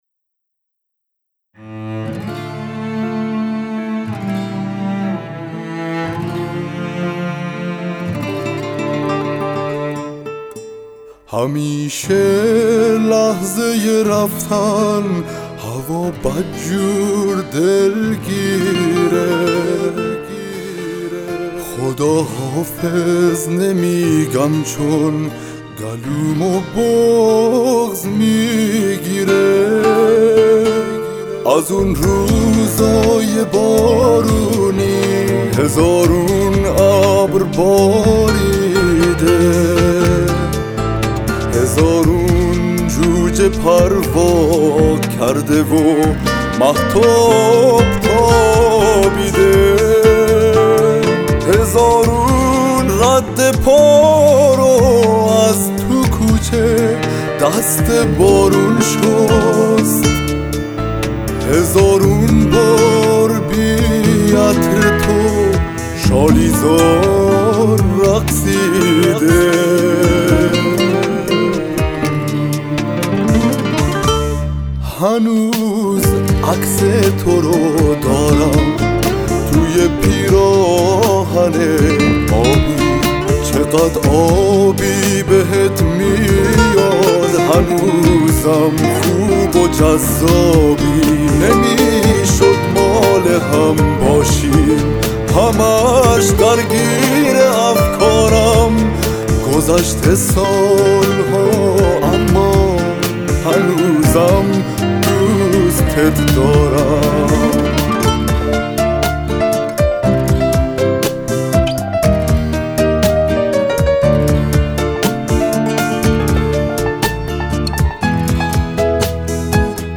گیتار اسپانیایی